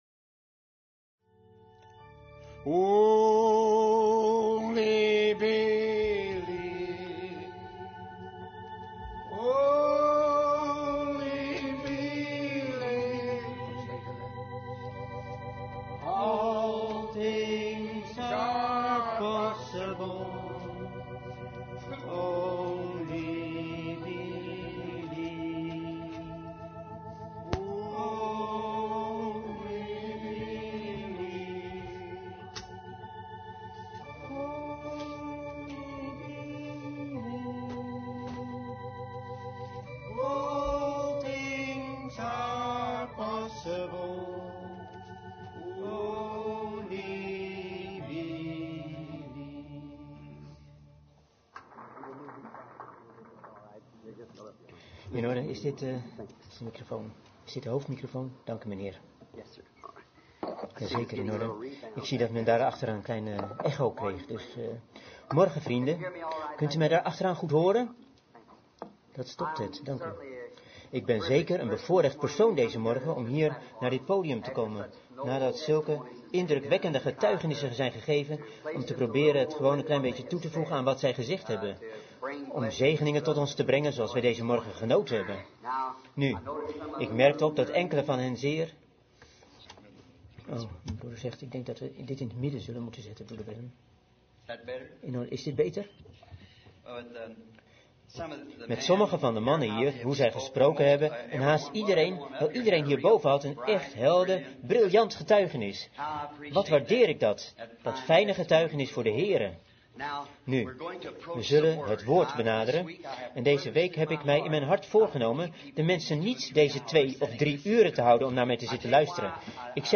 Vertaalde prediking "Turn on the light" door William Marrion Branham te Ramada inn, Phoenix, Arizona, USA, 's ochtends op zaterdag 25 januari 1964